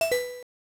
Sound effect of Casino Coin Lose in Super Mario 64 DS.
SM64DS_Casino_Coin_Lose.oga